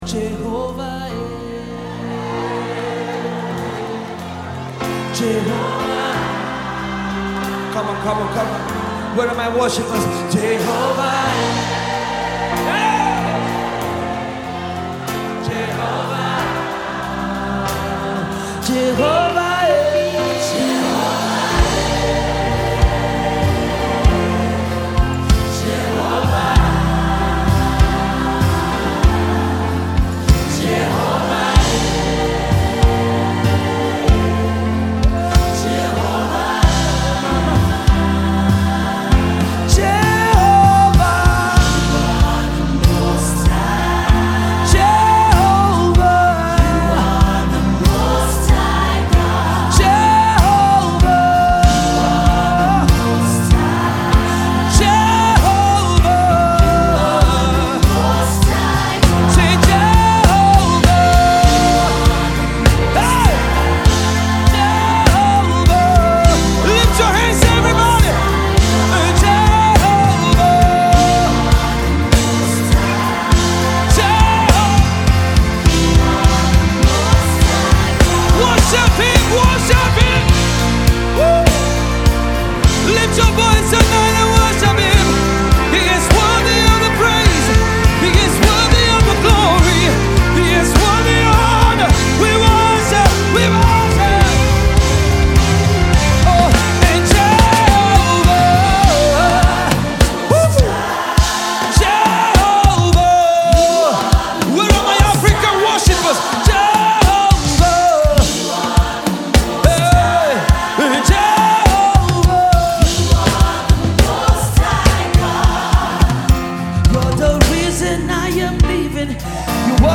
The medley seamlessly flows together